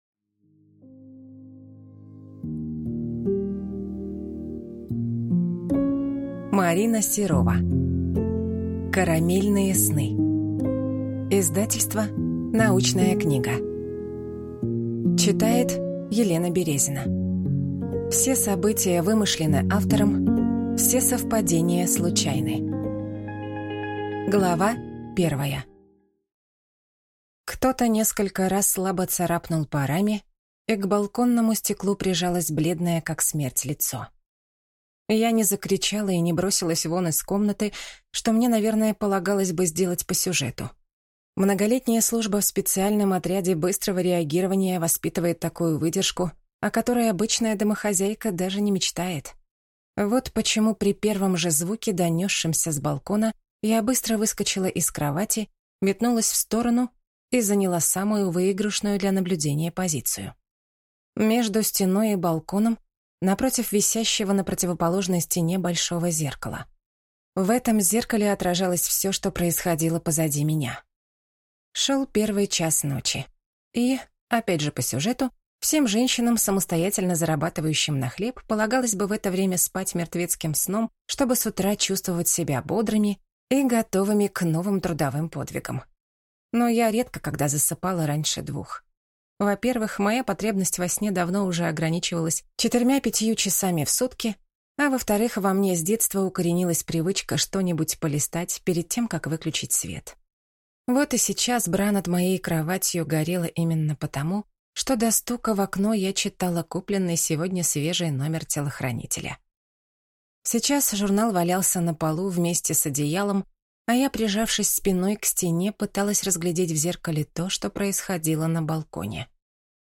Аудиокнига Карамельные сны | Библиотека аудиокниг
Прослушать и бесплатно скачать фрагмент аудиокниги